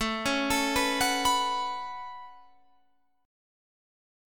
A6add9 chord